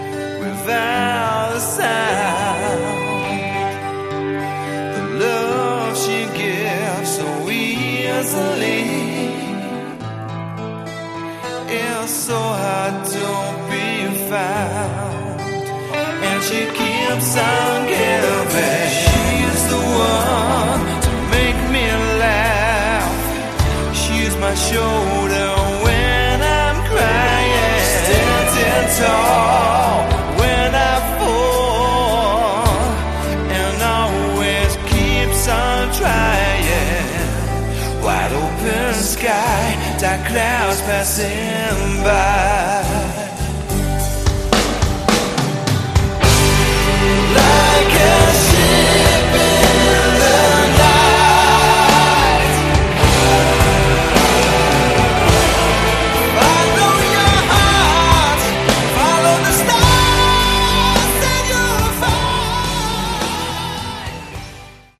Category: Melodic Hard Rock
Vocals
Guitars
Bass
Drums